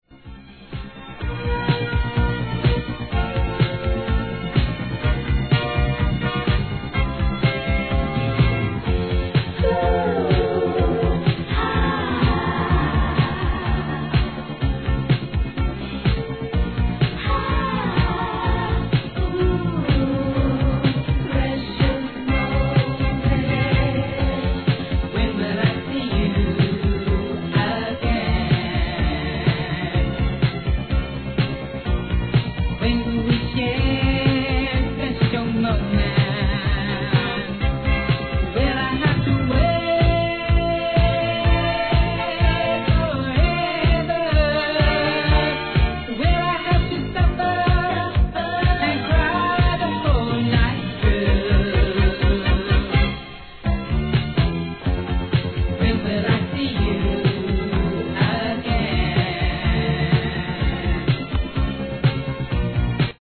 SOUL/FUNK/etc...
「フ〜〜〜ハ〜〜〜〜♪」のイントロ定番